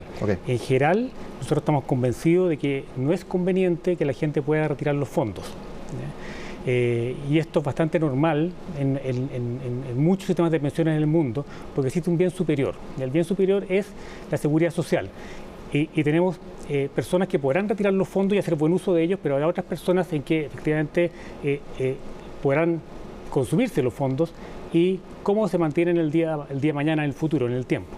La Constitución es bastante clara en el sentido de que las cotizaciones obligatorias pueden destinarse a un único fin que es la seguridad social y la seguridad social se paga por la vía de pensiones”, dijo en entrevista con CNN Chile.